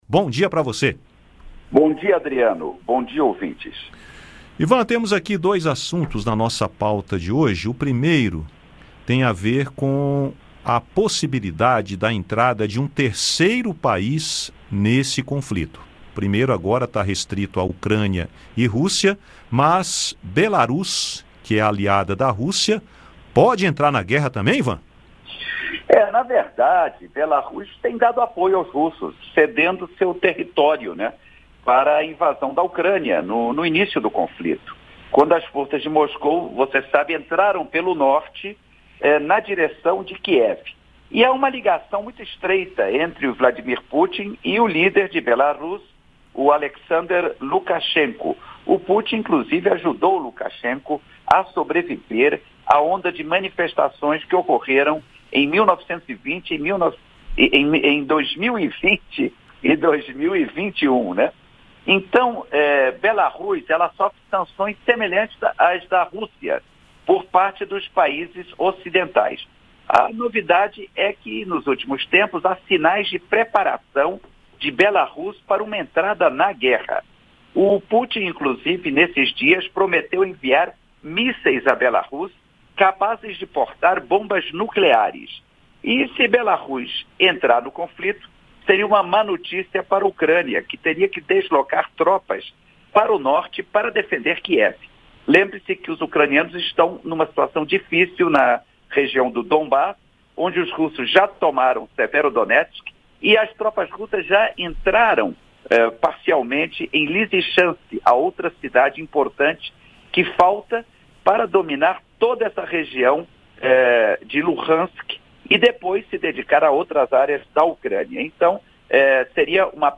jornalista e comentarista internacional, analisa as últimas notícias sobre os combates na Ucrânia e destaca o risco de Belarus entrar na guerra.